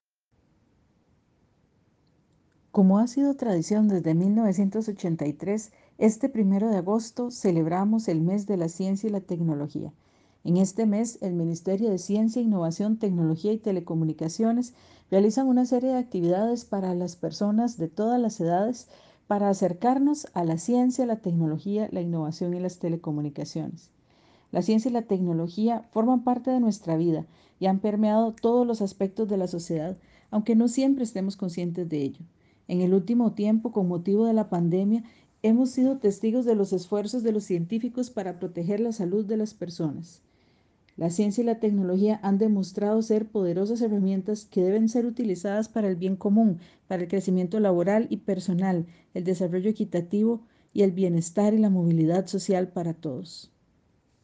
Declaraciones de la ministra Paola Vega Castillo en inauguración Mes de la ciencia y la tecnología 2021